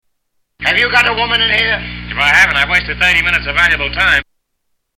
Tags: Movies The Marx Brothers The Marx Brothers Clips Duck Soup Comedy